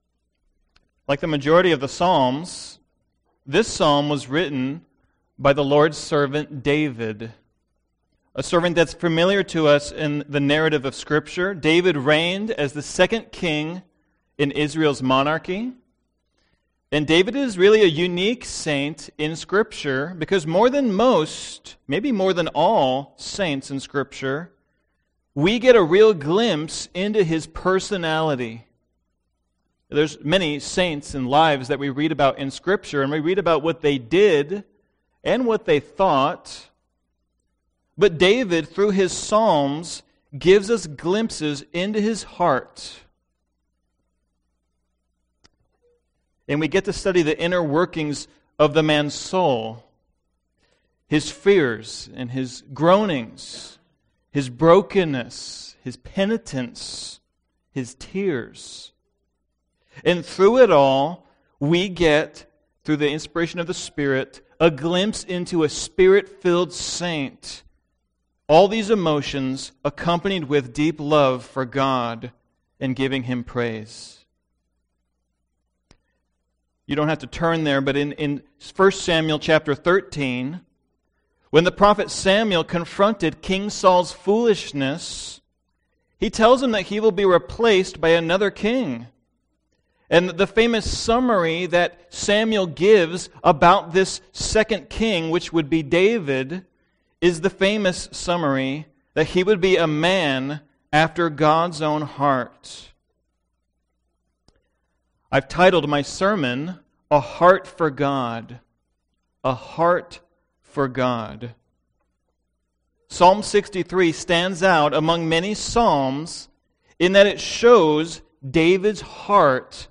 Podcast (heritage-valley-bible-church-sermons): Play in new window | Download